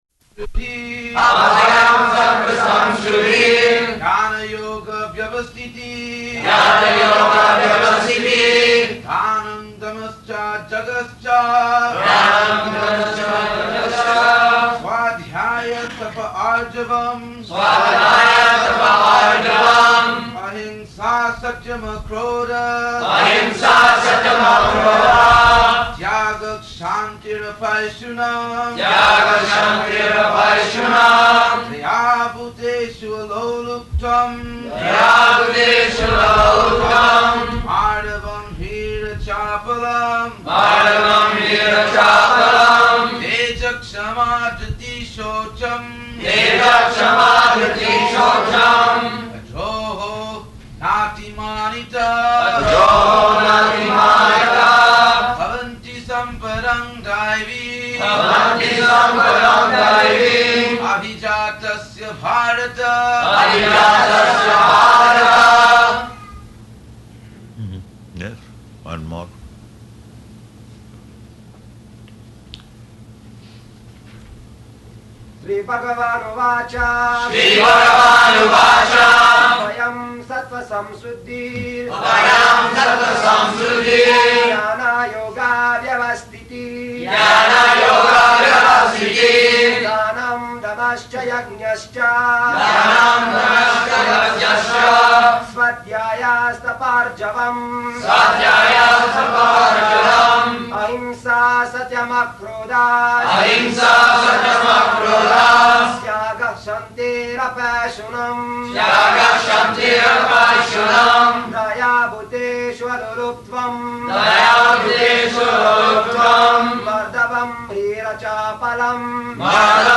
January 29th 1975 Location: Honolulu Audio file
[devotees repeat]